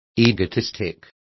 Complete with pronunciation of the translation of egotistic.